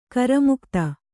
♪ karamukta